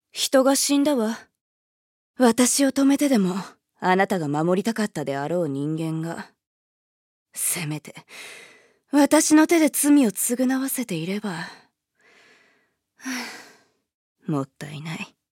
음성 대사